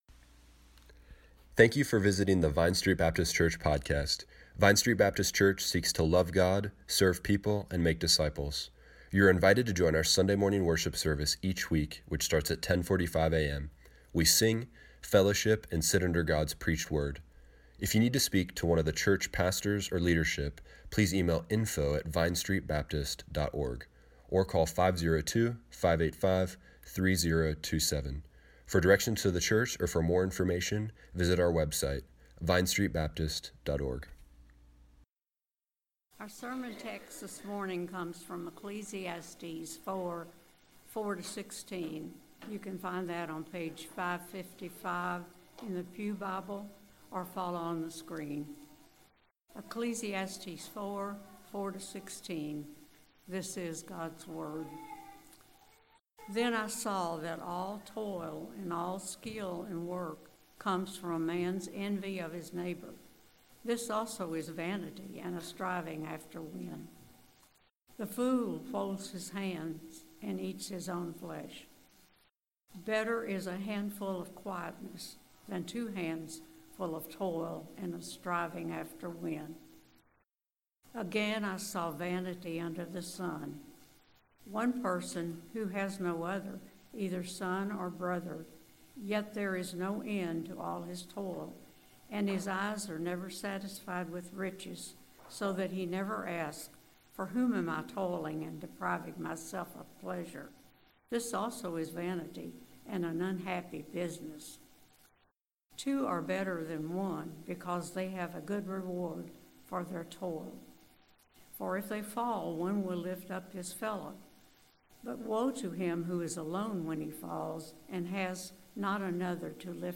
Service Morning Worship